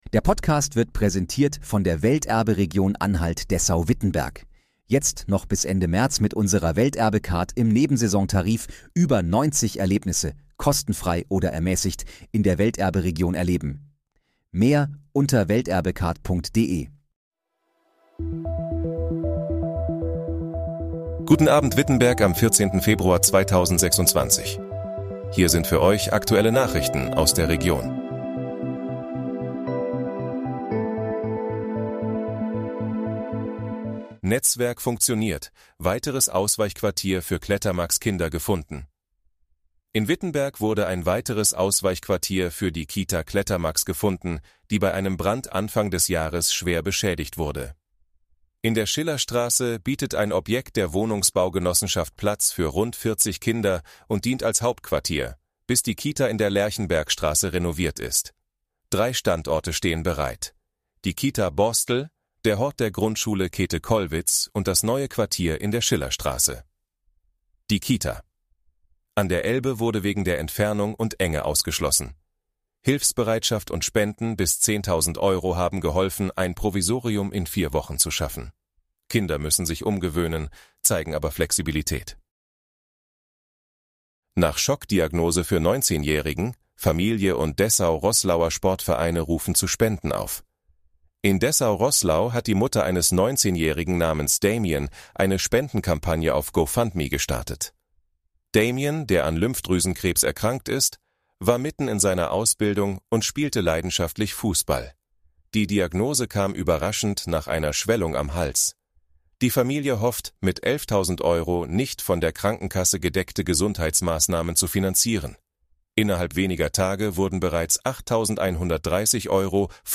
Guten Abend, Wittenberg: Aktuelle Nachrichten vom 14.02.2026, erstellt mit KI-Unterstützung
Nachrichten